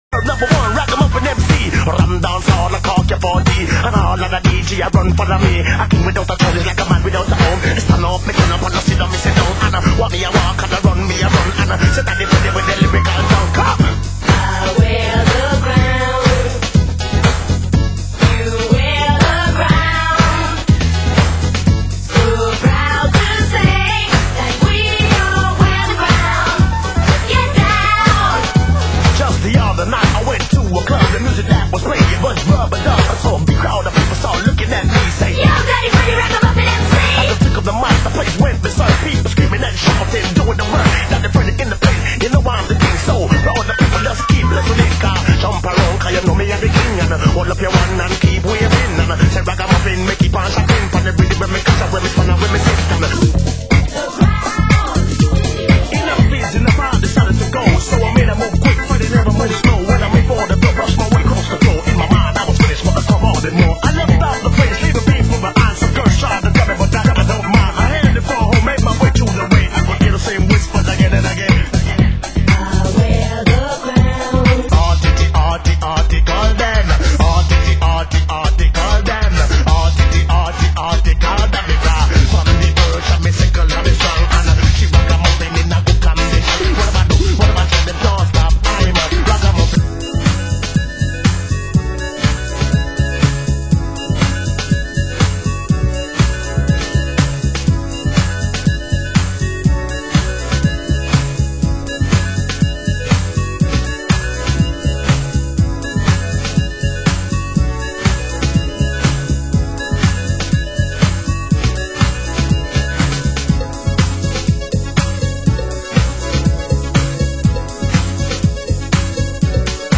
Format: Vinyl Double 12 Inch
Genre: US House